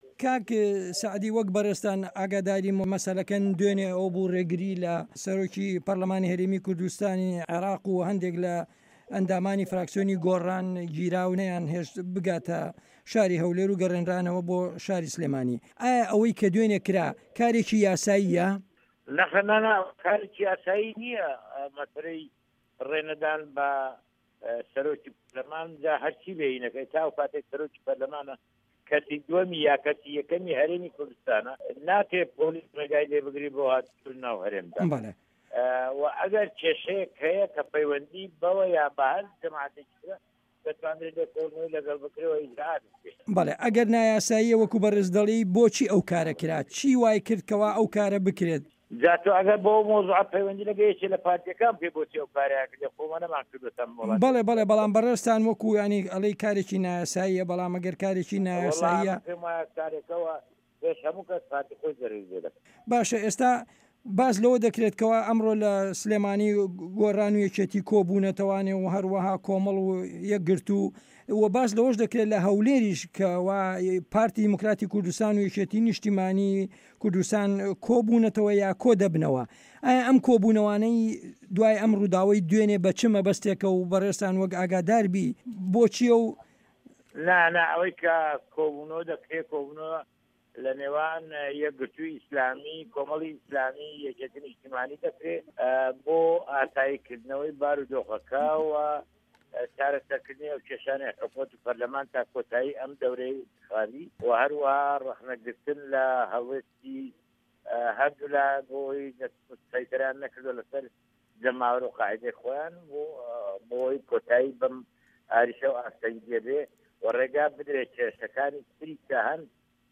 وتوێژ لەگەڵ سەعدی ئەحمەد پیرە